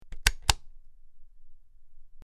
SwitchClicksOnOff PE447608
Switch; Clicks On And Off.